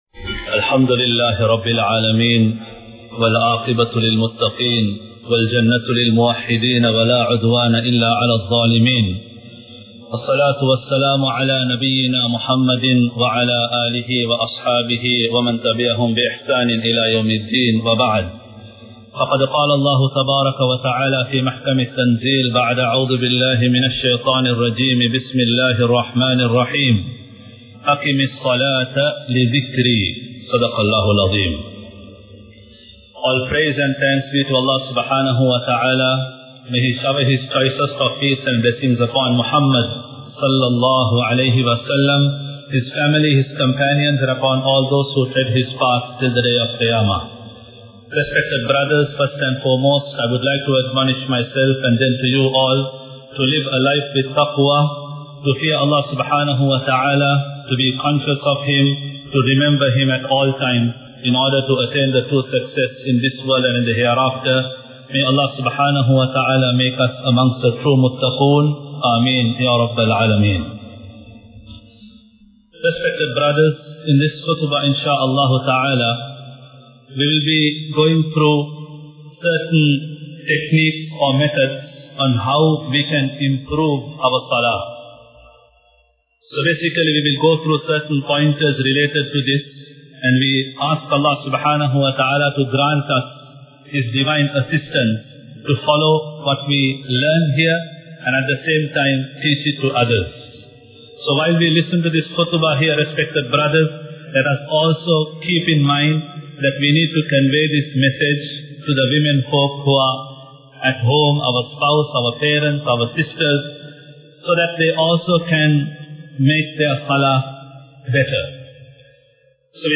Focus on Salah | Audio Bayans | All Ceylon Muslim Youth Community | Addalaichenai
Colombo 03, Kollupitty Jumua Masjith